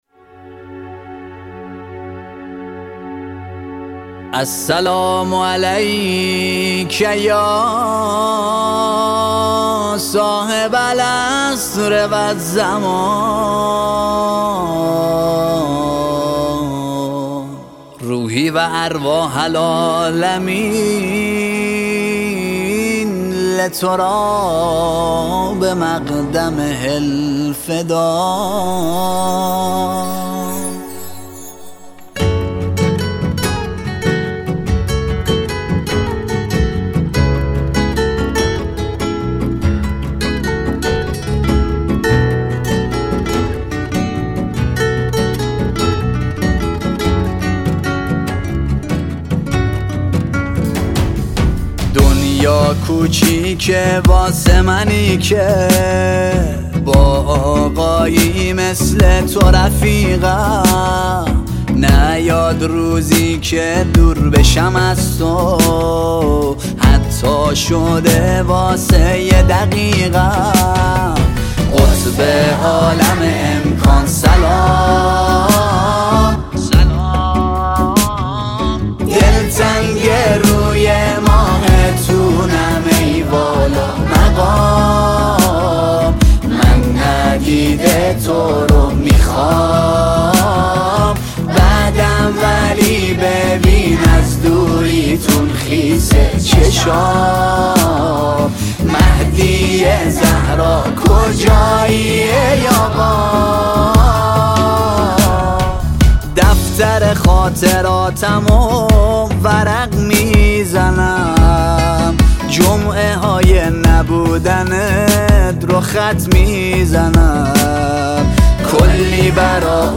ژانر: سرود ، سرود مذهبی ، سرود مناسبتی